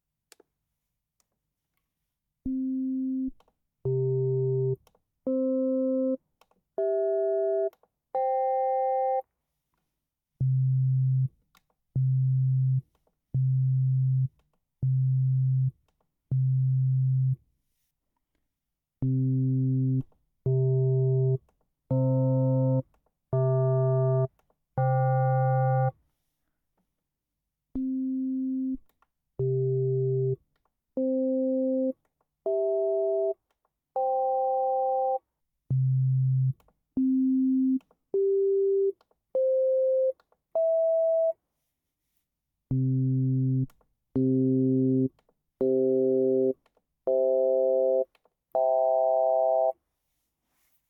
"Trying to figure out what the Casio VZ-1 does in RING mode with sine waves.